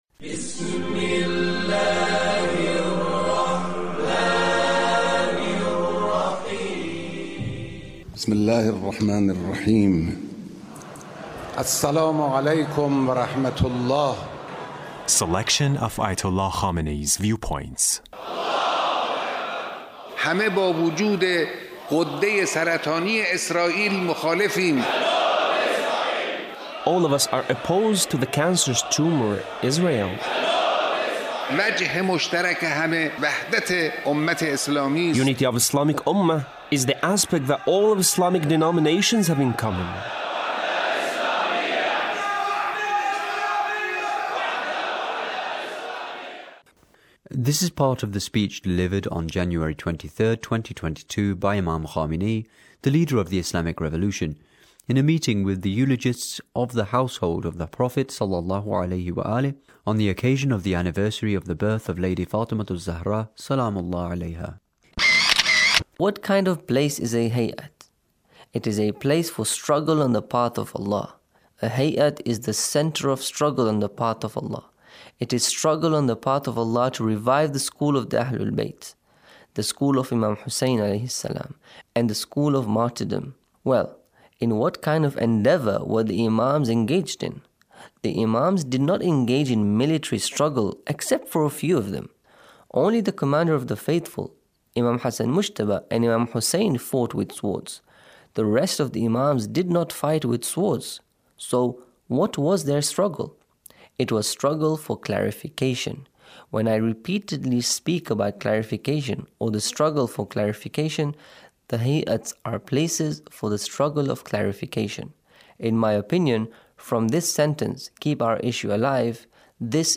Leader's Speech on a Gathering with eulogists of the Household of the Prophet